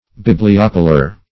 Meaning of bibliopolar. bibliopolar synonyms, pronunciation, spelling and more from Free Dictionary.
Search Result for " bibliopolar" : The Collaborative International Dictionary of English v.0.48: Bibliopolic \Bib`li*o*pol"ic\, Bibliopolar \Bib`li*op"o*lar\, a. [See Bibliopole .]